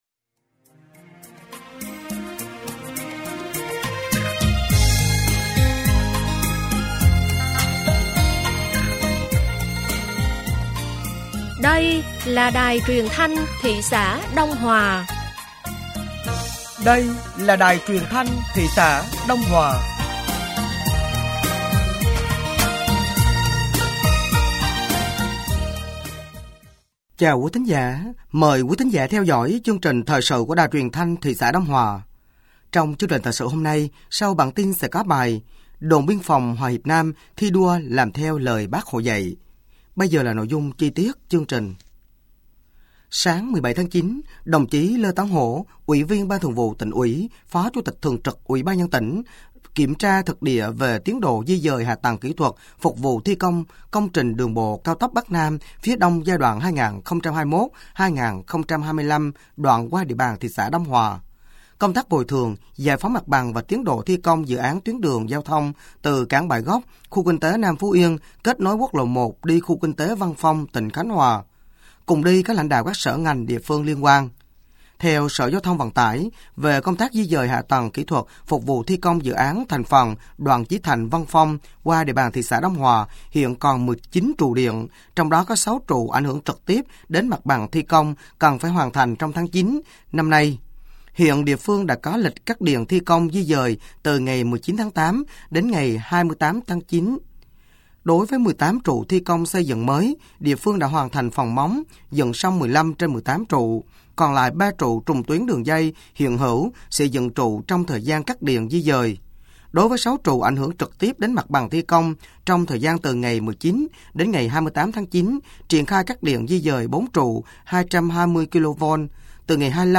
Thời sự tối ngày 17 và sáng ngày 18 tháng 9 nămn 2024